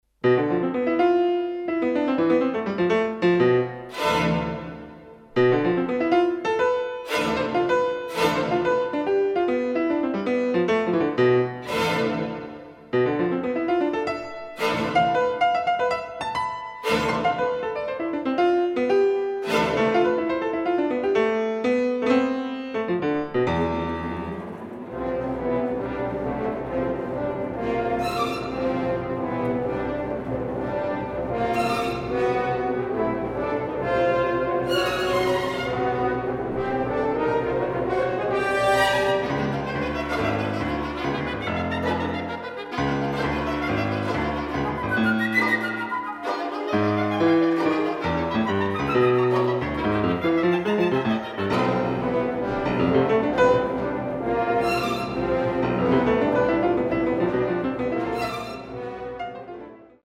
Žanrs: Simfoniskā mūzika
Diatoniska čakona